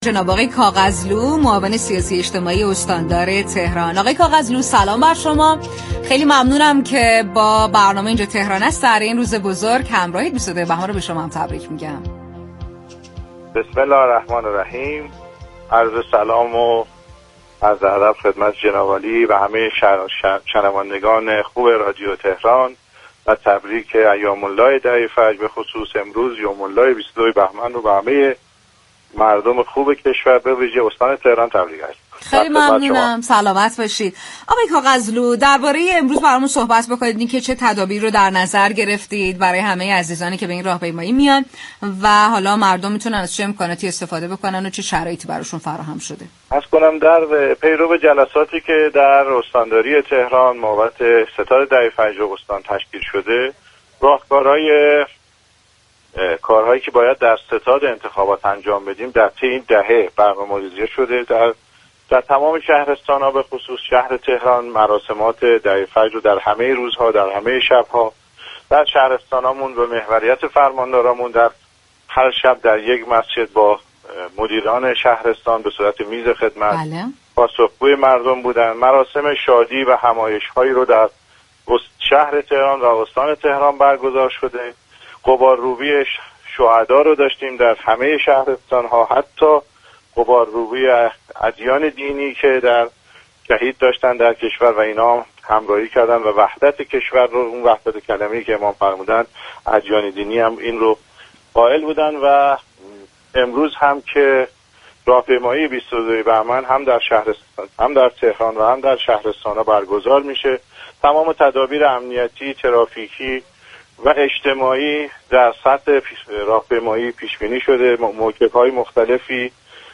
به گزارش پایگاه اطلاع رسانی رادیو تهران، حسین كاغذلو معاون سیاسی و اجتماعی استاندار تهران در گفت و گو با «اینجا تهران است» ویژه برنامه چهل و ششمین سالروز پیروزی انقلاب اسلامی اظهار داشت: راهپیمایی 22 بهمن امروز در تهران و تمام استان‌های كشور در حال برگزاری است و میز خدمت در مسیرهای راهپیمایی مستقر شده است.